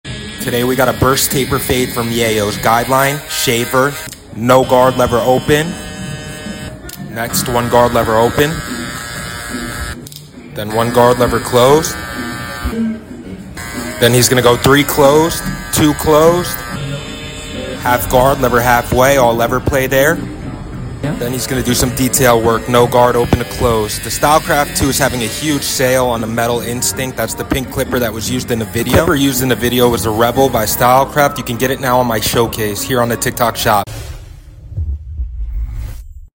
STEP BY STEP w/ Barber POV + Voiceover